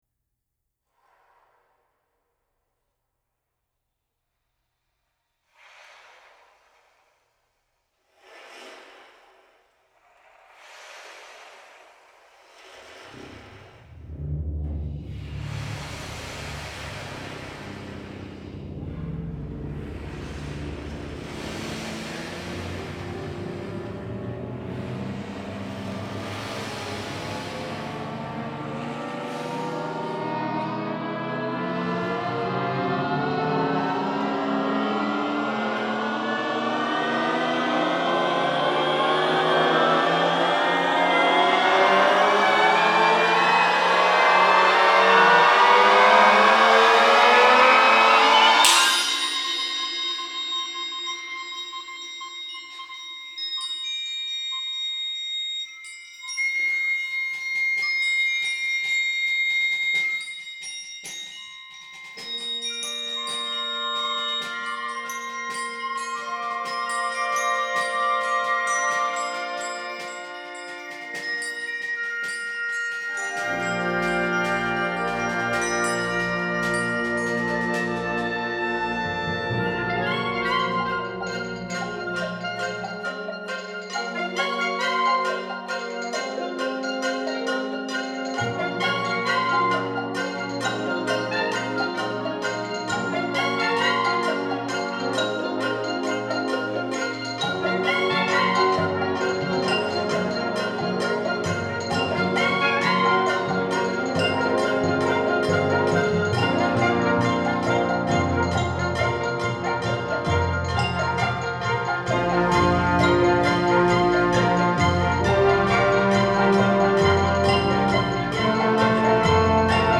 Plantilla vídeo + banda